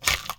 Close Combat Break Bone 7.wav